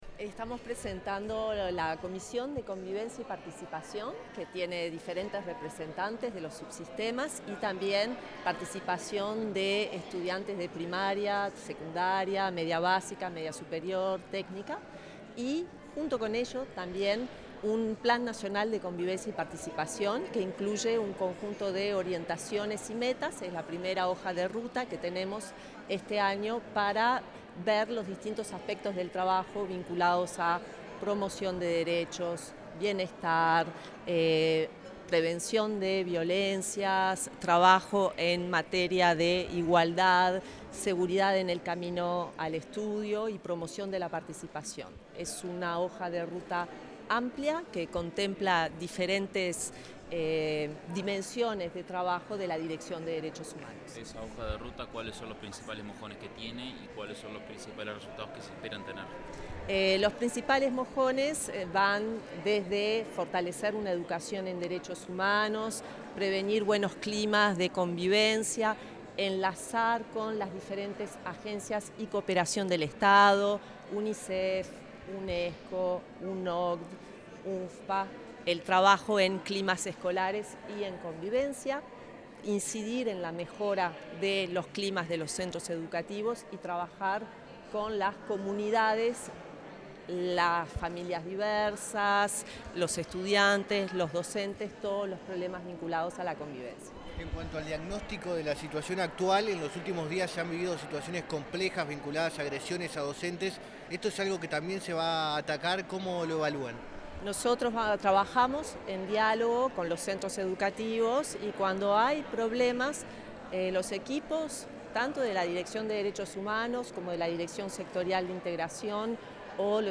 Declaraciones de la directora de Derechos Humanos de la ANEP, Nilia Viscardi
Declaraciones de la directora de Derechos Humanos de la ANEP, Nilia Viscardi 30/10/2025 Compartir Facebook X Copiar enlace WhatsApp LinkedIn La directora de Derechos Humanos de la Administración Nacional de Educación Pública (ANEP), Nilia Viscardi, realizó declaraciones en la presentación de la Comisión de Convivencia y Participación.